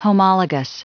Prononciation du mot homologous en anglais (fichier audio)
Prononciation du mot : homologous